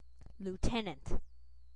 loo-TEN-ənt, /lə-/, generally associated with the United States. See lieutenant.
En-us-lieutenant-2.ogg.mp3